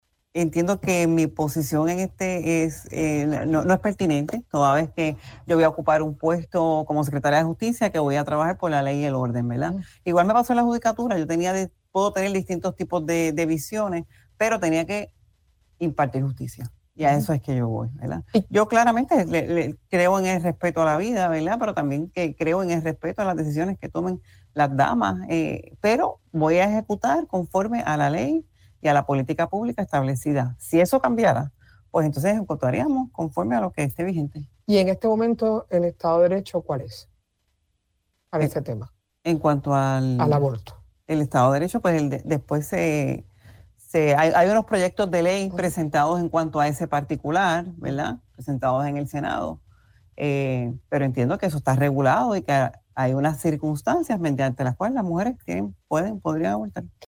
La designada secretaria del Departamento de Justicia, Lourdes Lynnette Gómez Torres, aseguró en entrevista para Radio Isla que actuará conforme a la ley y el derecho en situaciones que traten el tema del aborto.